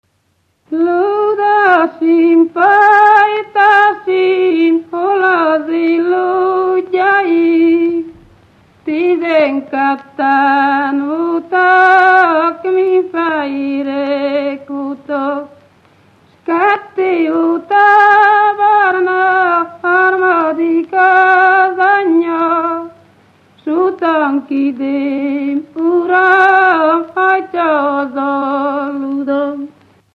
Moldva és Bukovina - Moldva - Lészped
Stílus: 7. Régies kisambitusú dallamok
Szótagszám: 6.6.6.6
Kadencia: 4 (b3) 4 1